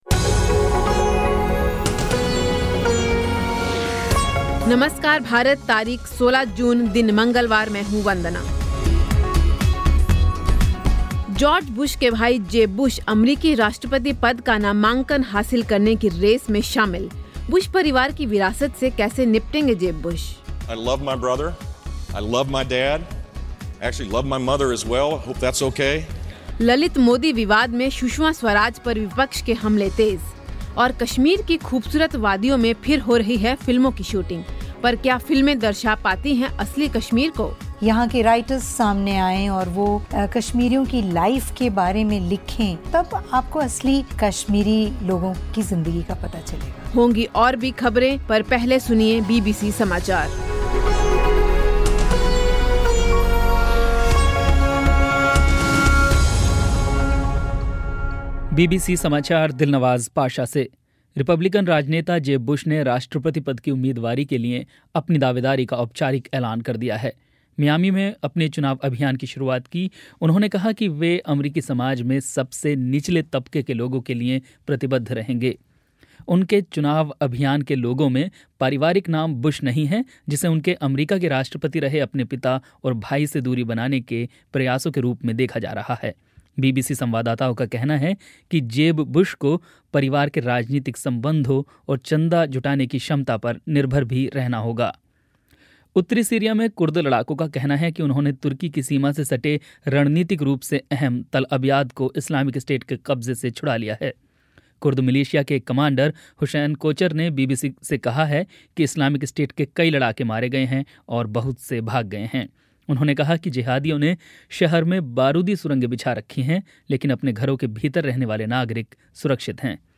साथ ही सुनिए खेल की ख़बरें